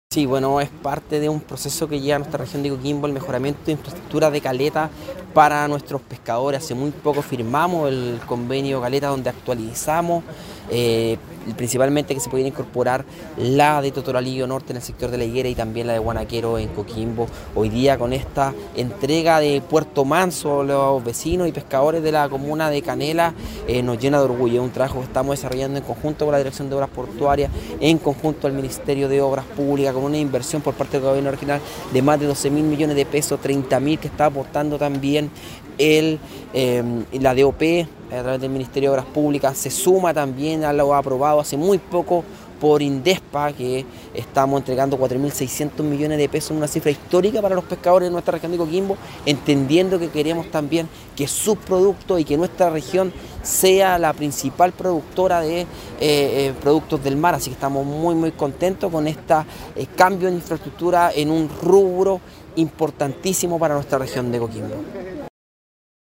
El gobernador, Darwin Ibacache, destacó el impacto de este proyecto para la comunidad.
GOBERNADOR-REGIONAL-DARWIN-IBACACHE.mp3